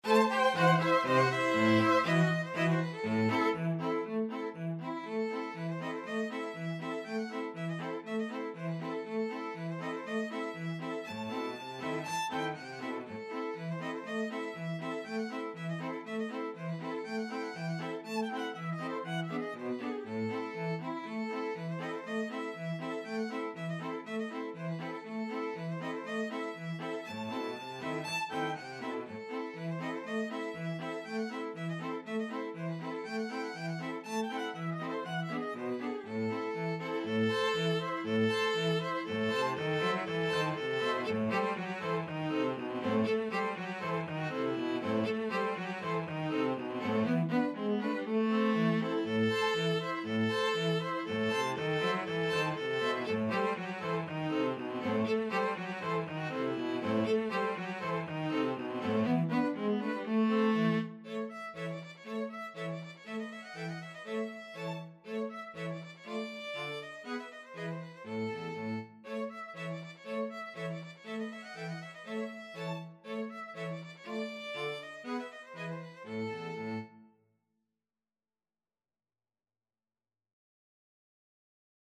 Traditional Trad. Freilechs von der Chuppe (klezmer) String Quartet version
Violin 1Violin 2ViolaCello
E minor (Sounding Pitch) (View more E minor Music for String Quartet )
2/4 (View more 2/4 Music)
Moderate Klezmer = 120
Traditional (View more Traditional String Quartet Music)
world (View more world String Quartet Music)